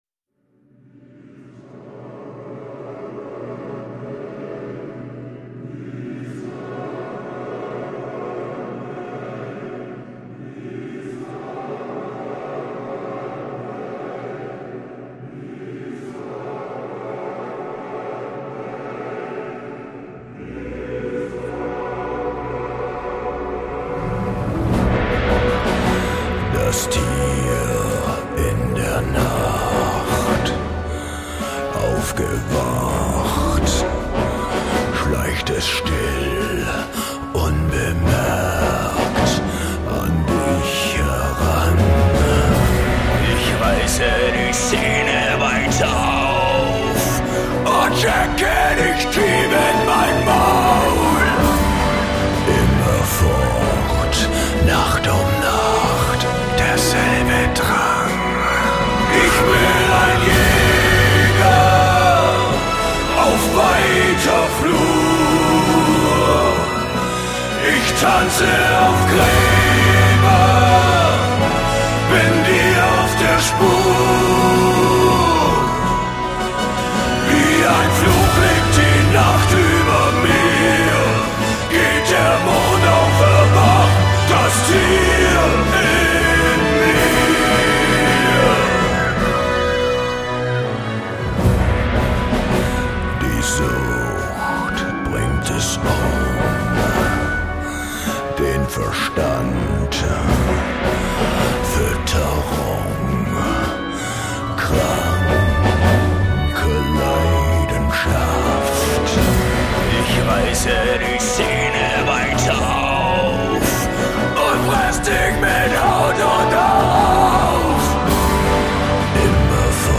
Жанр: Industrial, Alternative